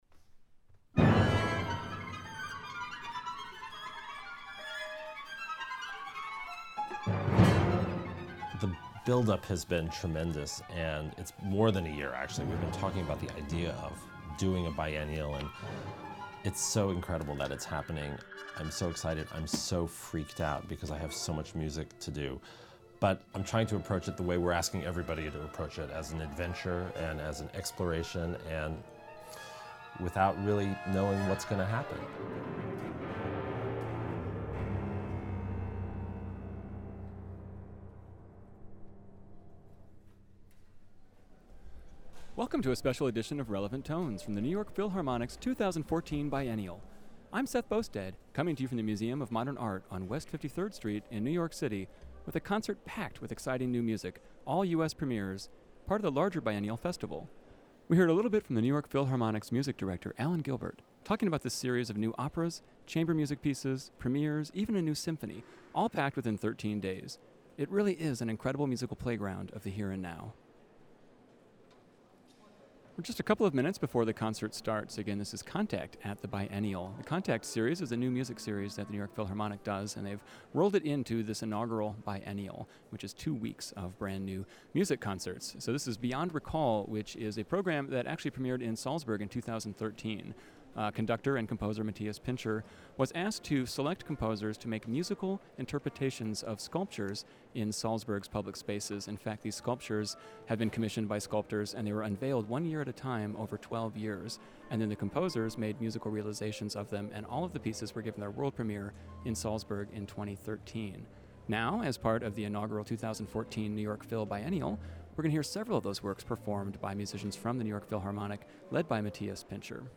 soprano
bass-baritone